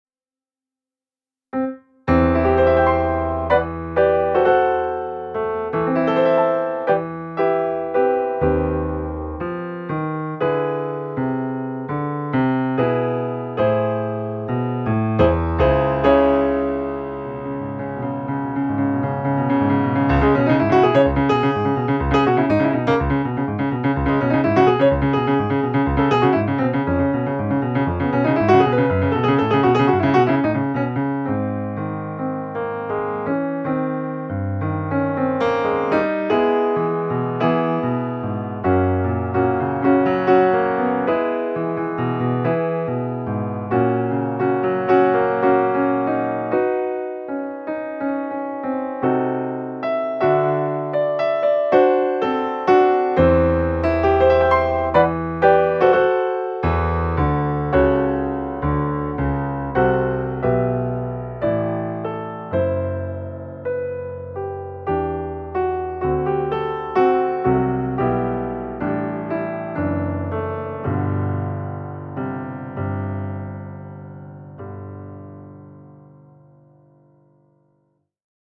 PianoSolo1.mp3